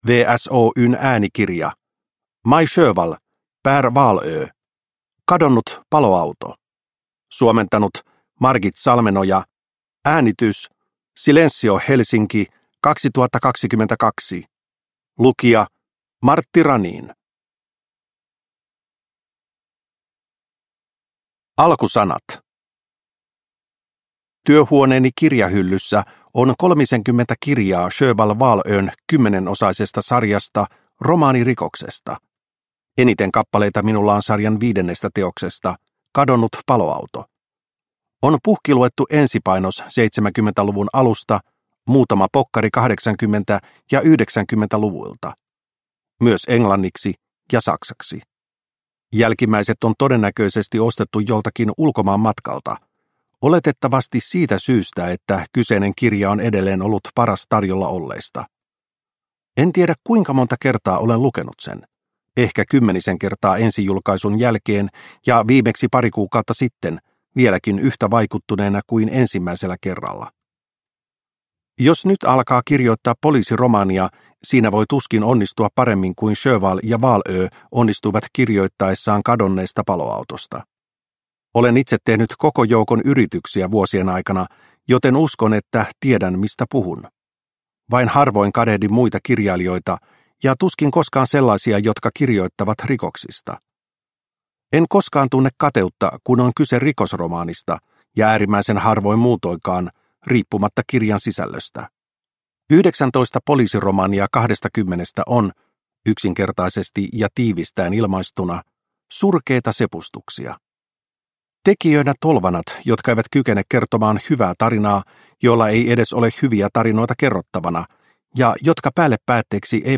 Kadonnut paloauto – Ljudbok – Laddas ner